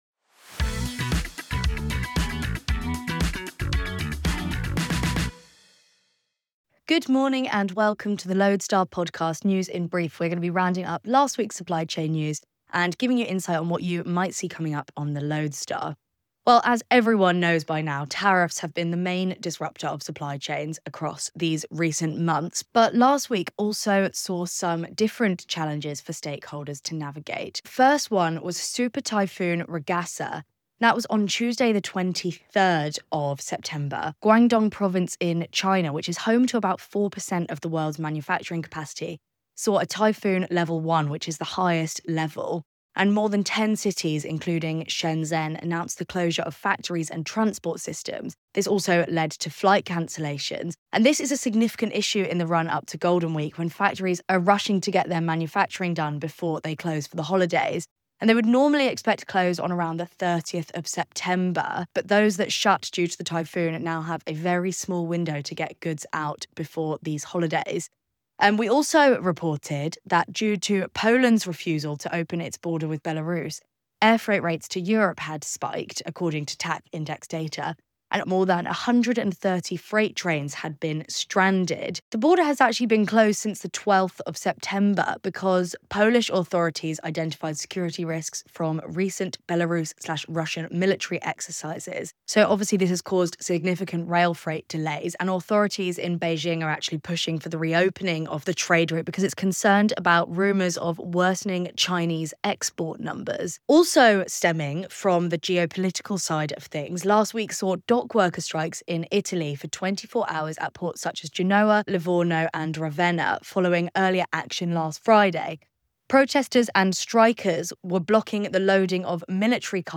News in Brief podcast | Week 39 2025 | Typhoons, tariffs and Cosco's global push